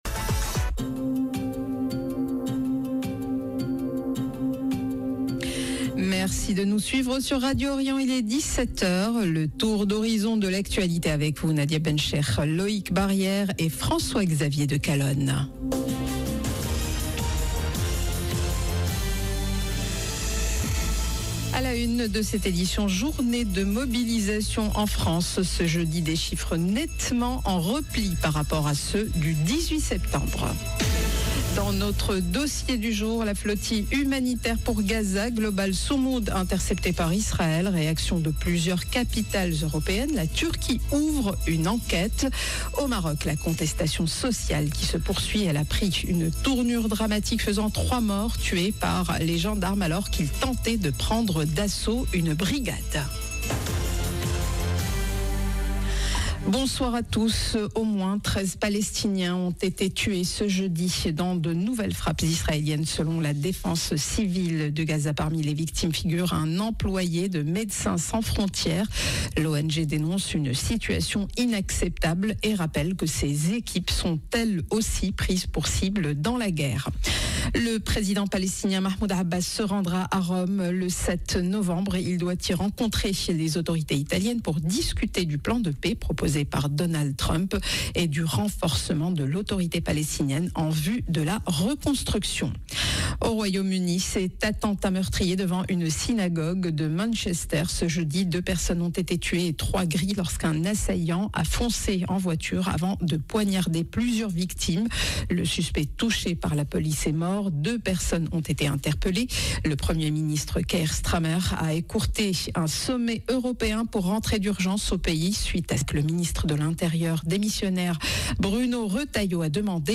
Journal de 17H du 02 octobre 2025